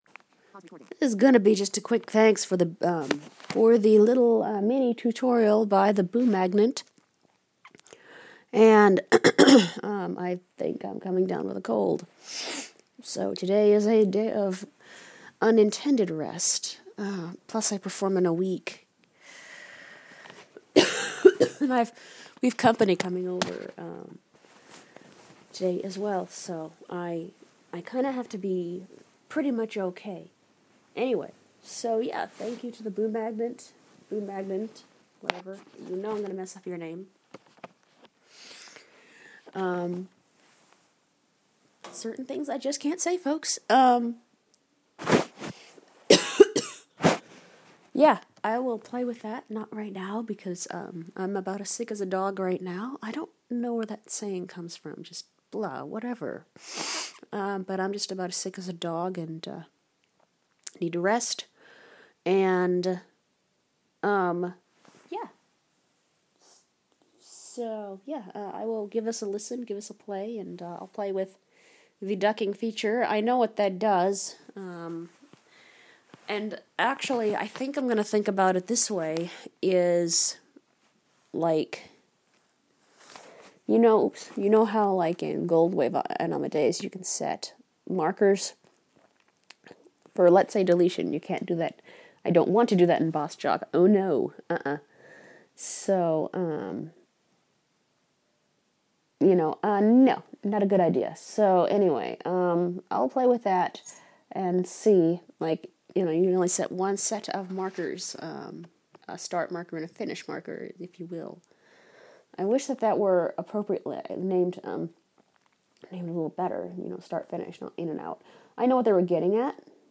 And sorry I sound sick.